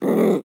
Minecraft Version Minecraft Version snapshot Latest Release | Latest Snapshot snapshot / assets / minecraft / sounds / mob / wolf / cute / growl2.ogg Compare With Compare With Latest Release | Latest Snapshot
growl2.ogg